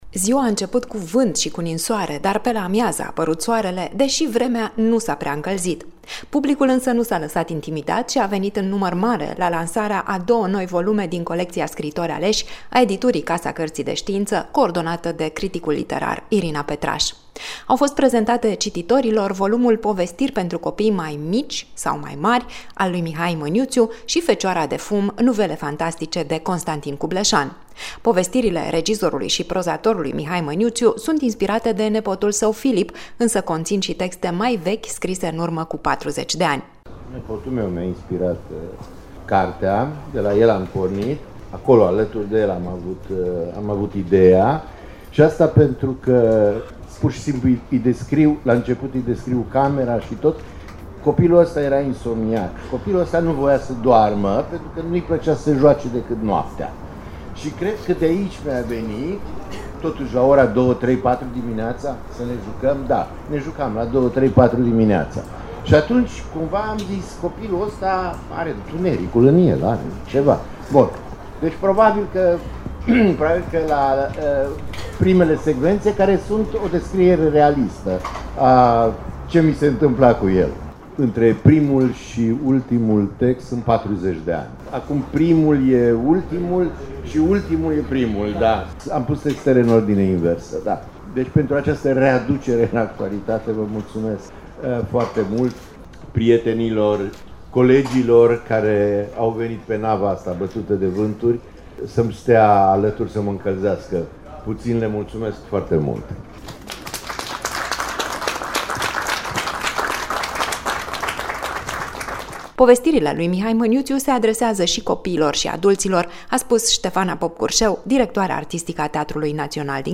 Târgul de Carte Gaudeamus Radio România, ziua a doua.